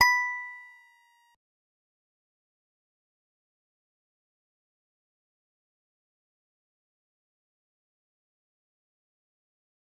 G_Musicbox-B6-mf.wav